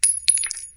shellDrop1.wav